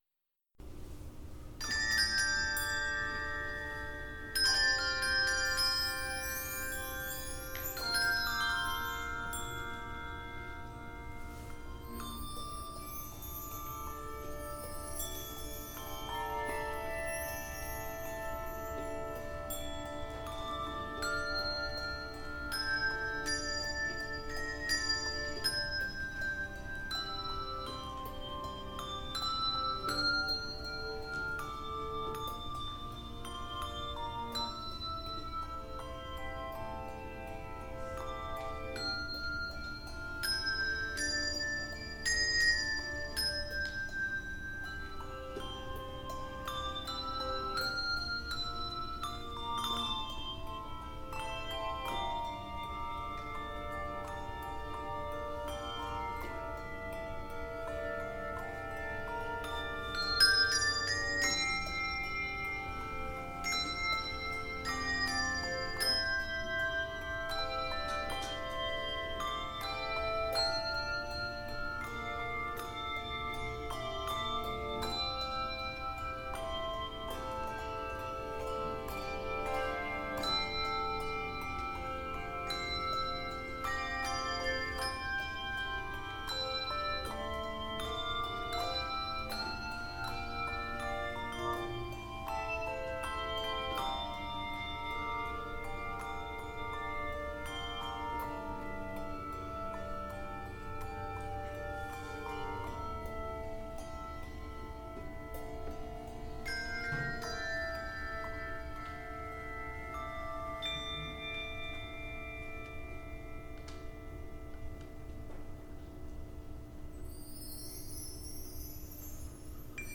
Note: Recording begins at measure 14.